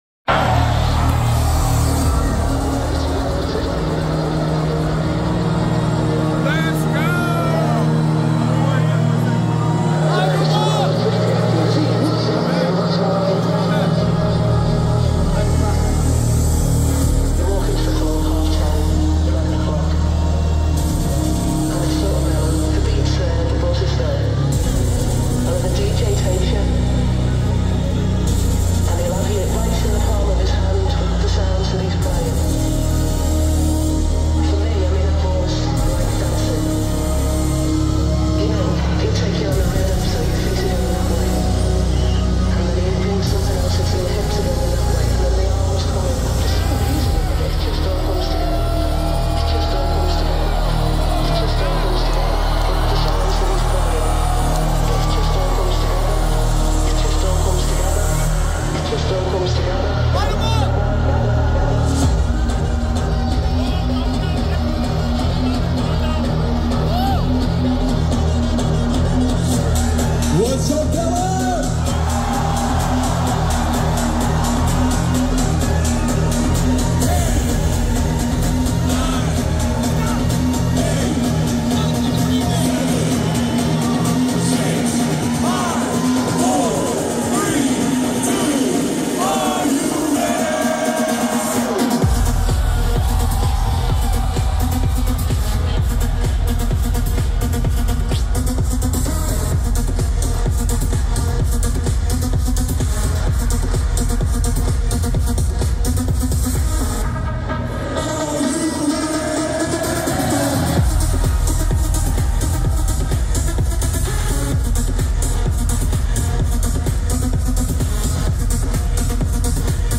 House mix
Grand opening